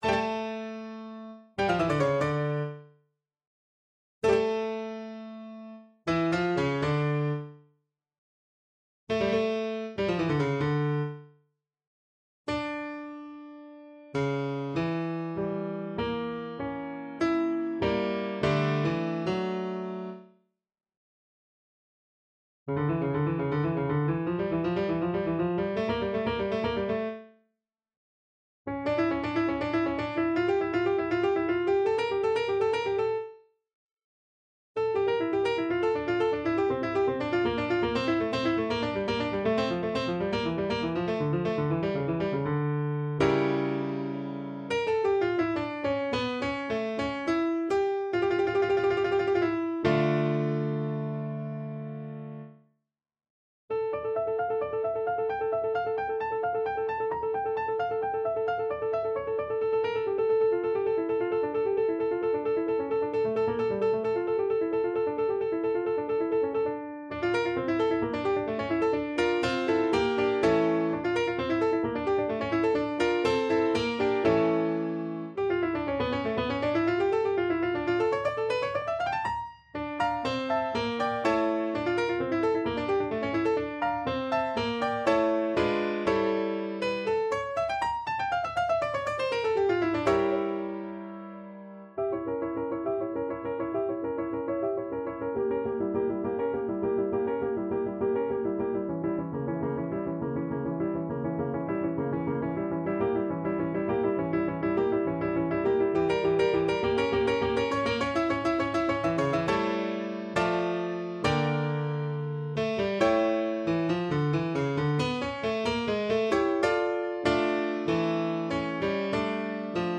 for Violin and Viola (version 1)
» 442Hz